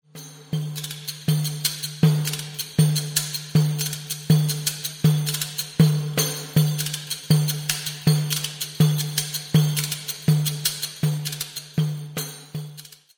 Both CDs contain a variety of all Percussion Music